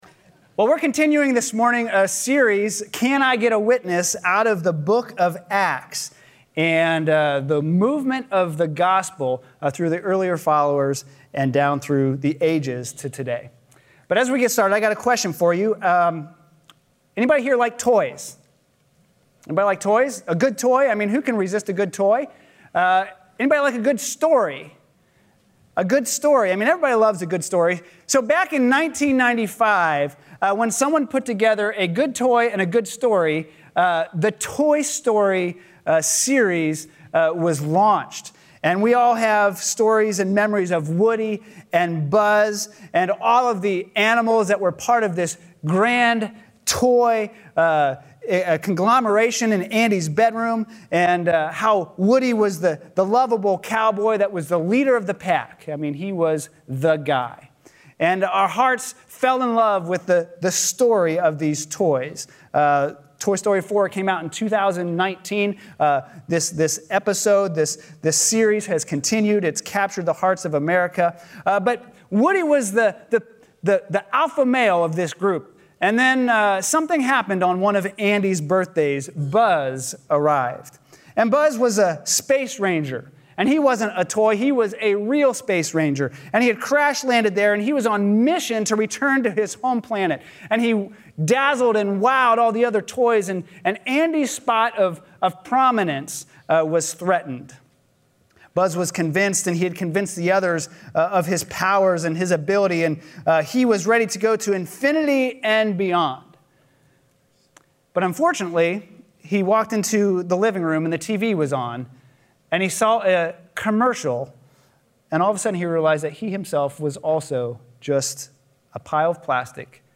A sermon from the series "Can I Get a Witness."